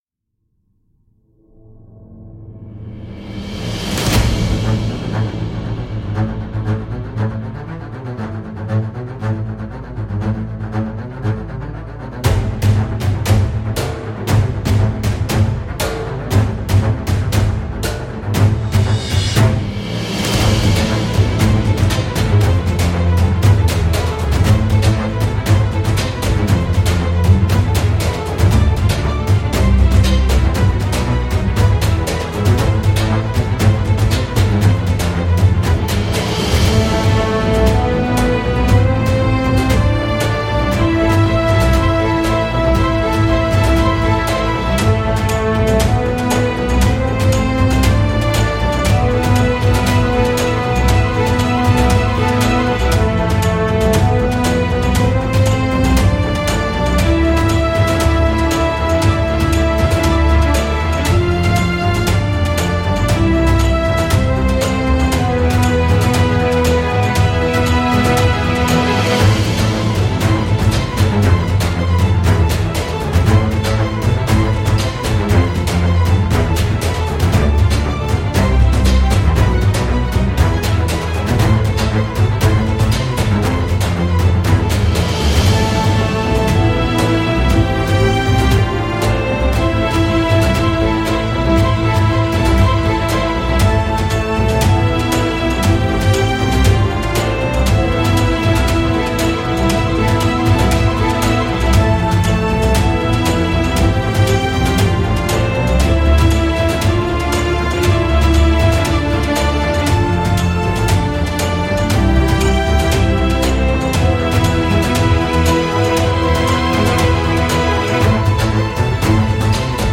Genre: Instrumental Tema: épica, instrumental, medieval